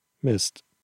Utspraak von Mist (Audio)
Utspraak op Platt: /mɪst/